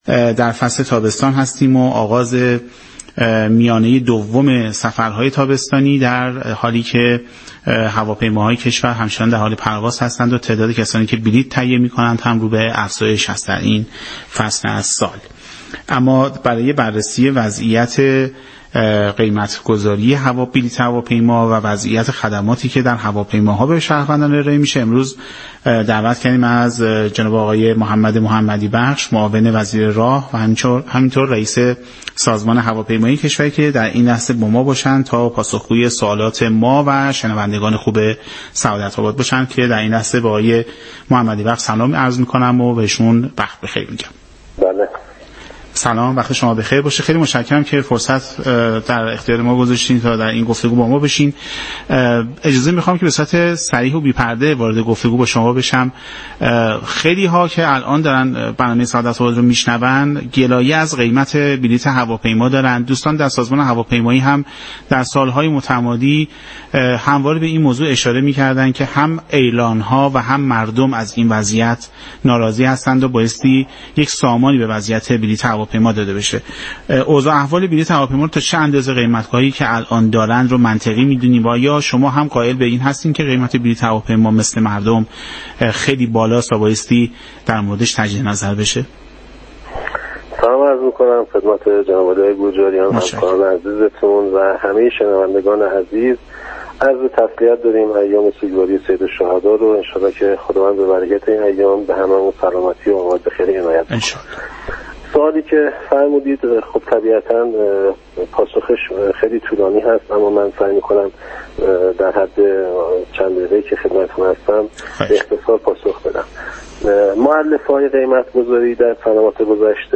به گزارش پایگاه اطلاع رسانی رادیو تهران، محمد محمدی‌بخش معاون وزیر راه و رئیس سازمان هواپیمایی كشوری در گفت و گو با برنامه سعادت اباد 11 مردادماه با اشاره به اینكه در سنوات گذشته ، طی برنامه های پنجم و ششم قانون متنوع سازی و آزادسازی بلیط هواپیما اجرایی و پس از آن هم به صورت قانونی ابلاغ شدگفت : از سال 94 به بعد قیمت بلیط مطابق با عرضه و تقاضا ارائه شد.